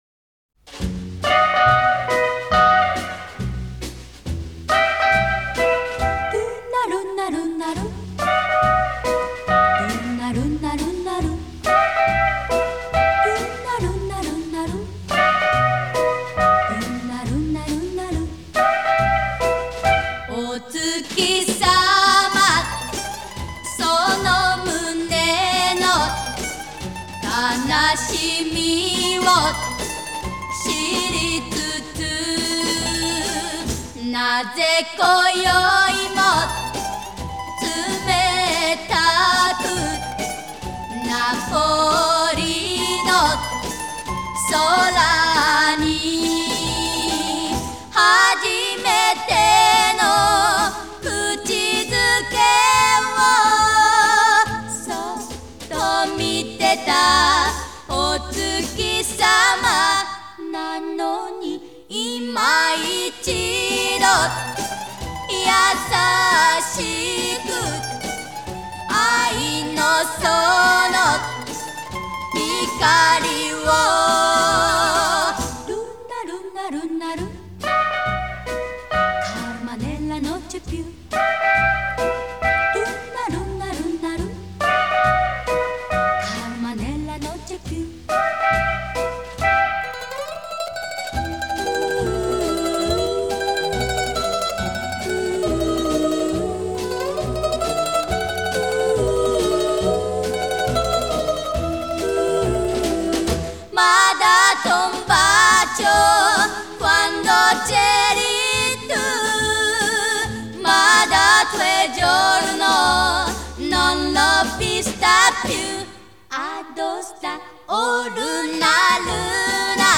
Звук неплохой. Японский ремастеринг.
Жанр: Classic Pop